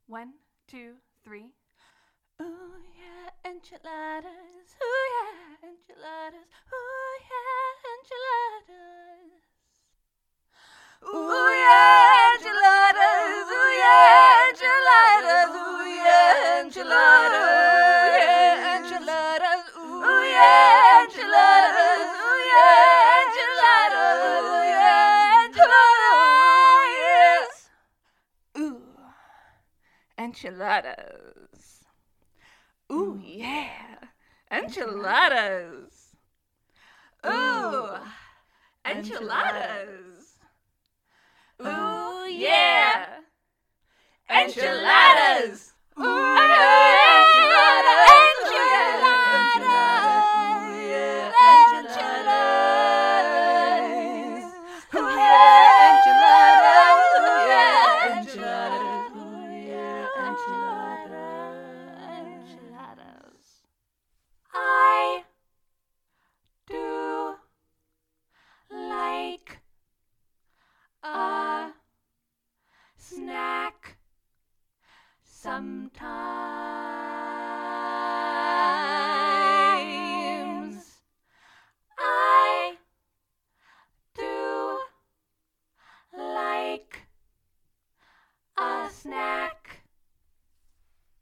"ooh yeah enchiladas" sung with passion in 4 part harmony for a minute and a half
It's all to 110BPM and I can send the four voices separate if useful. a26331c92b34cd75.mp3 1